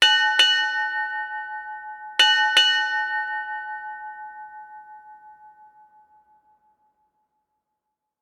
Four Bells,Ship Time
4-bells bell ding maritime nautical naval sailing seafaring sound effect free sound royalty free Sound Effects